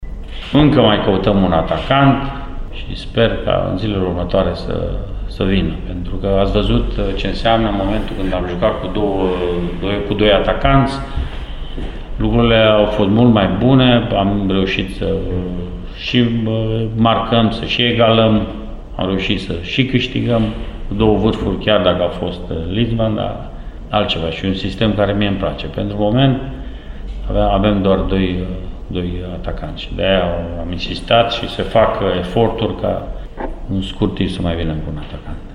Deși se află înaintea rundei cu numărul 11, UTA încă mai caută jucători, lucru recunoscut de Mircea Rednic înaintea confruntării de astăzi, referindu-se strict la compartimentul ofensiv acolo unde există doar două opțiuni Cooper și Vucenovic: